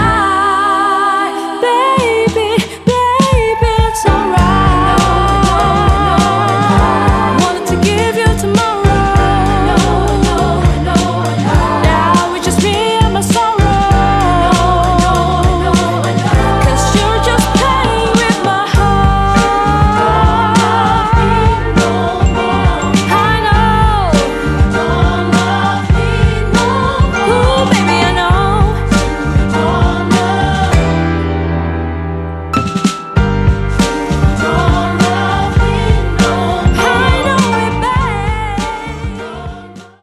lagu bernuansa mid-tempo
Dibalut dengan elemen musik soul dan R&B